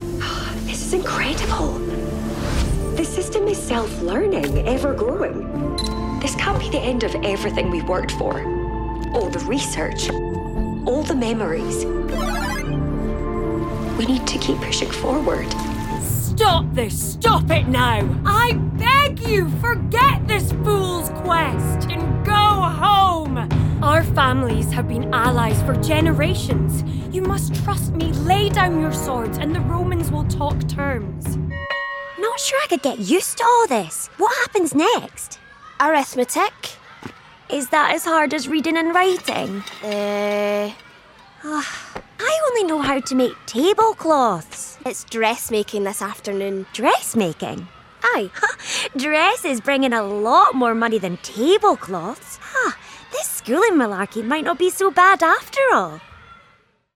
Anglais (écossais)
Jeux vidéo
I’m a Scottish full-time Voiceover Artist with a broadcast quality studio.
Fully sound insulated studio with acoustic treatment
Mic: Rode NT2-A
Contralto